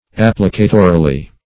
applicatorily - definition of applicatorily - synonyms, pronunciation, spelling from Free Dictionary Search Result for " applicatorily" : The Collaborative International Dictionary of English v.0.48: Applicatorily \Ap"pli*ca*to*ri*ly\, adv. By way of application.